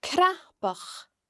The cn sound can also be heard in cnapach (lumpy):